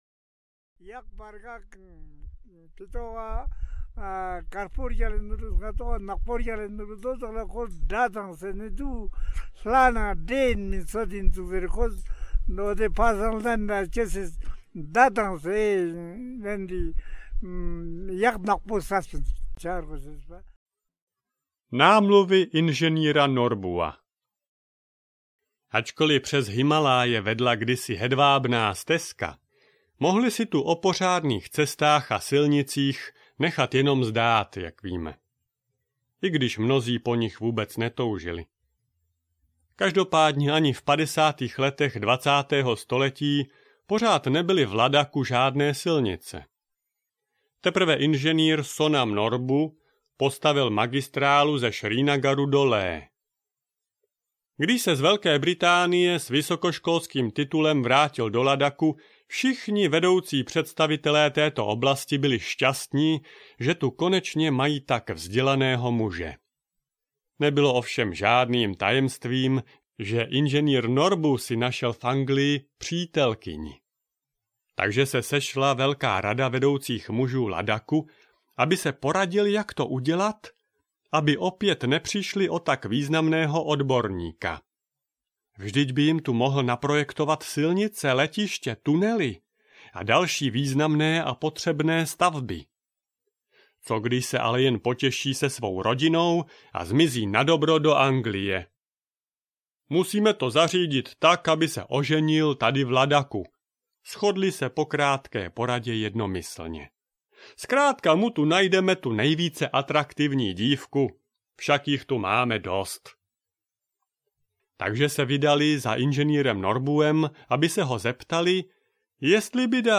Tato audiokniha vás vezme na dalekou cestu do Malého Tibetu.
Audiokniha je doplněna ukázkami ladacké hudby a mluveného slova.